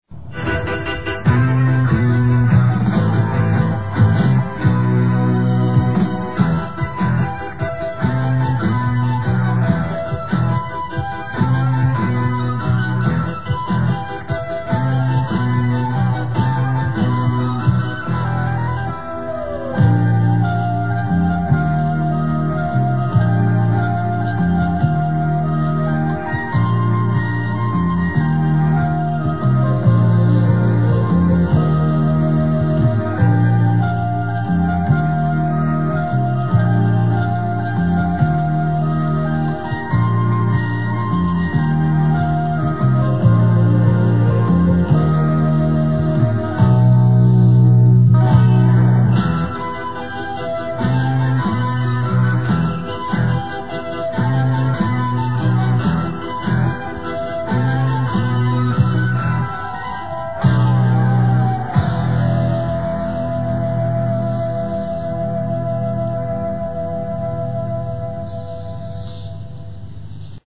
Closing theme.